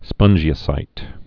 (spŭnjē-ə-sīt)